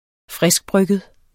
Udtale [ -ˌbʁœgəð ]